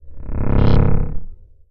SCIFI_Energy_Pulse_05_mono.wav